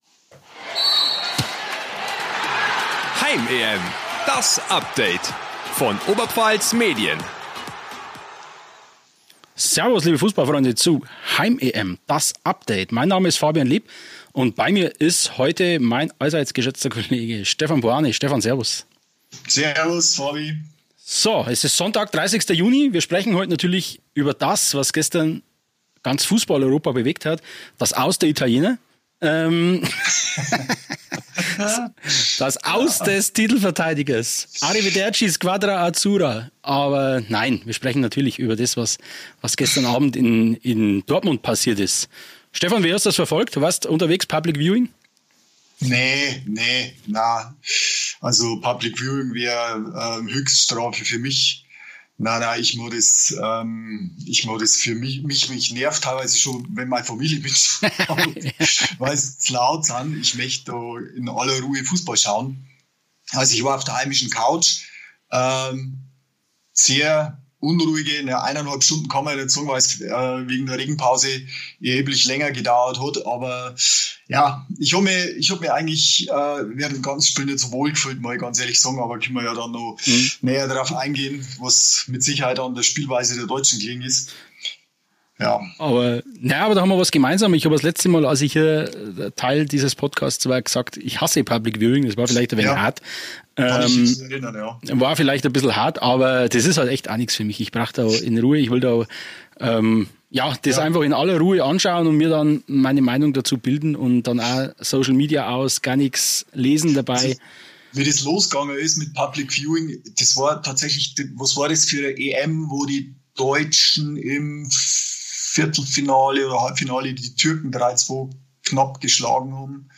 Oder war der 2:0-Sieg nicht auch vielmehr Ausdruck einer neuen deutschen Stärke: dem Pragmatismus. Die beiden Redakteure sind sich nicht immer einer Meinung und liefern sich einen Schlagabtausch – fast so intensiv wie Deutschland und Dänemark im Dortmunder Stadion.